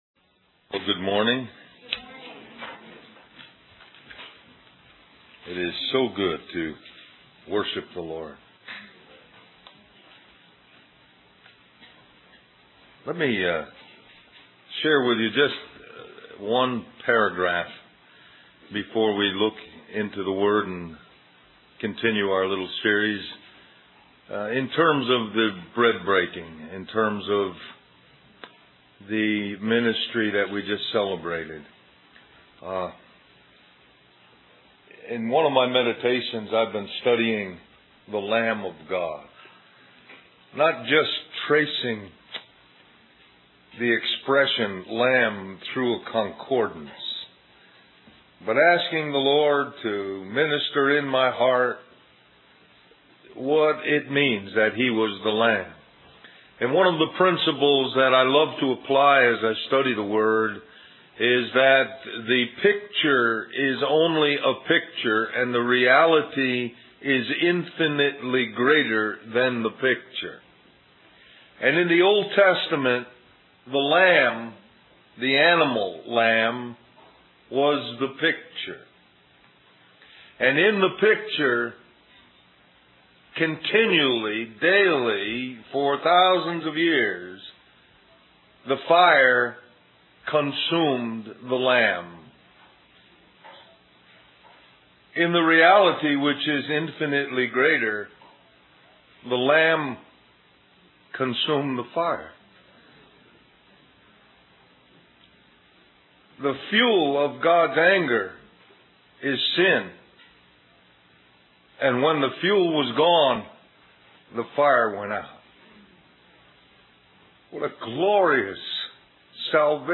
Del-Mar-Va Labor Day Retreat